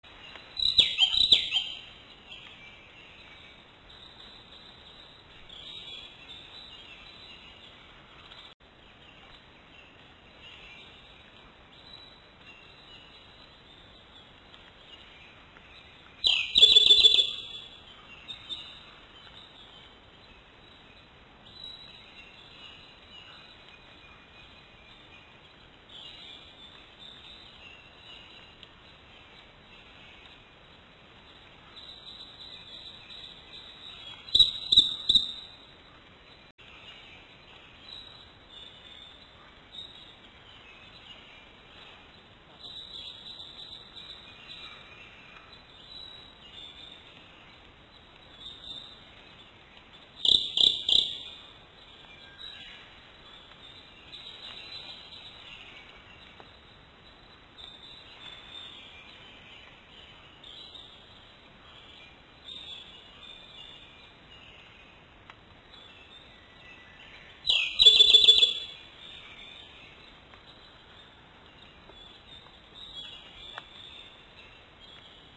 ノ　ゴ　マ　属   コ　ル　リ　１　1-05-06
鳴 き 声：地鳴きは細くチッ、チッ、チッと鳴く。
に似ているが、声量はない。
鳴き声１